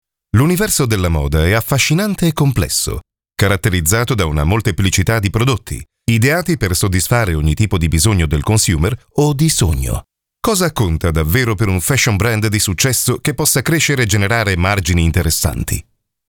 Commercieel, Speels, Volwassen, Warm, Zakelijk
Corporate